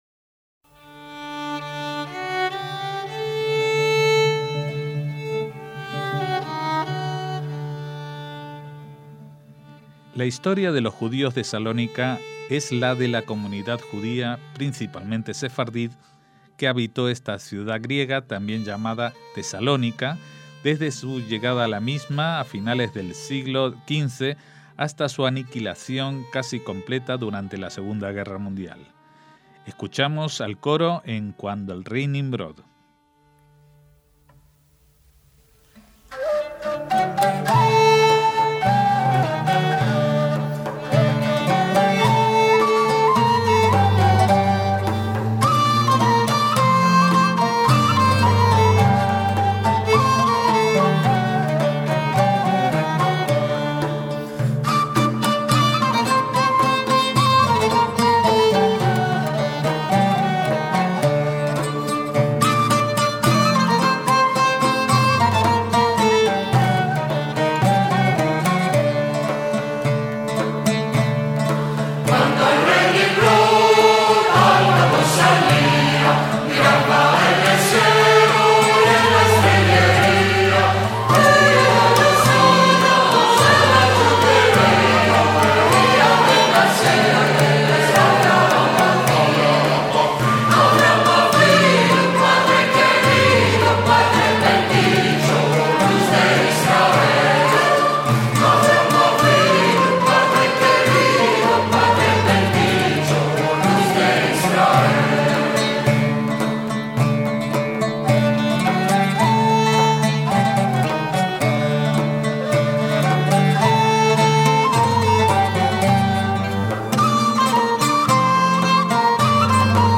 En la actualidad viven en Salónica unos 1.300 judíos, lo que hace de su comunidad la segunda de Grecia, después de la de Atenas. Entre sus múltiples objetivos está recuperar sus propias raíces culturales, por ejemplo, a través un coro que aborda el repertorio tradicional.